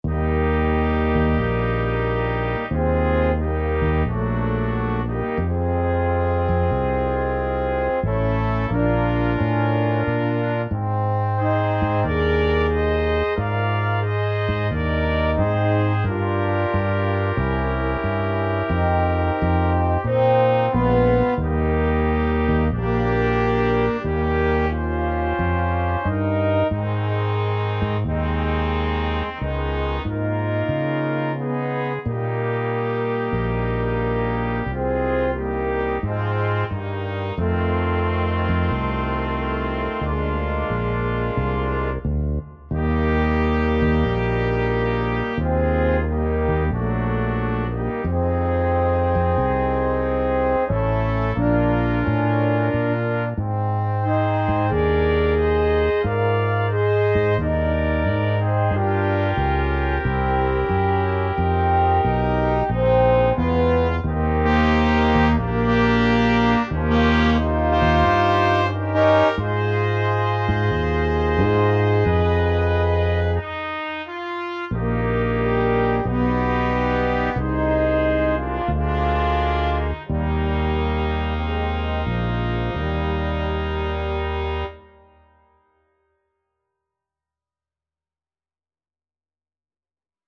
Dychové kvinteto Značky: Inštrumentalne